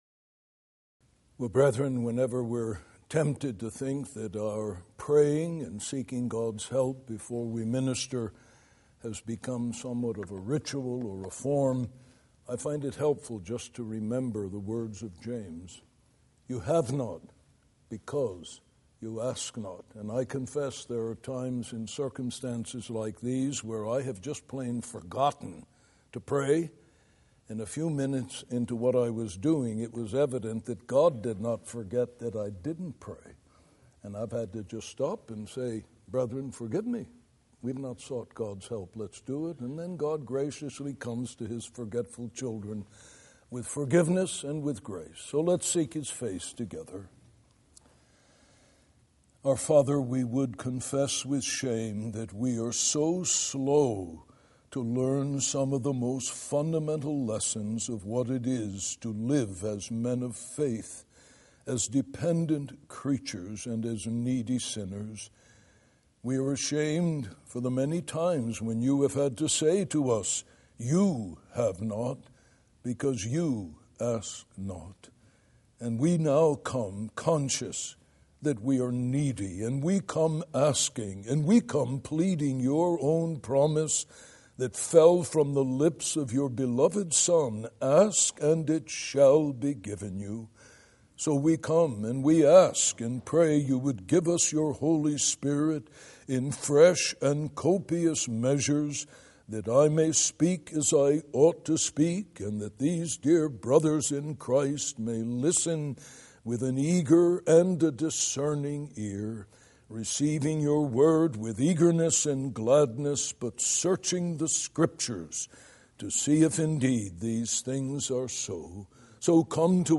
Lecture 8 – The Lord’s Supper – Being Taught that We may Teach